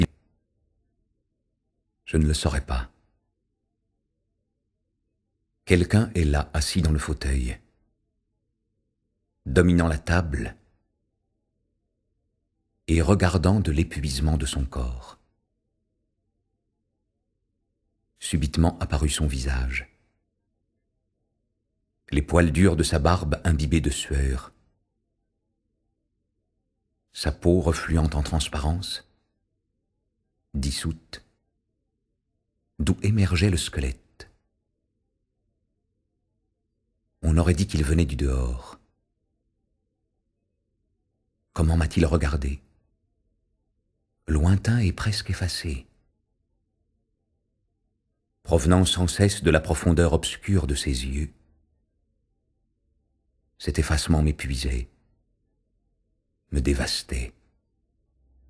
Get £1.85 by recommending this book 🛈 Cette anthologie présente de nombreux poèmes d'auteurs tels que Varoujan, Siamanto ou encore Komitas. Volontairement épuré dans sa forme, ce concert poétique révèle quelques aspects du monde intérieur arménien, au travers de poèmes et musiques intimement liées à l’histoire de ce peuple.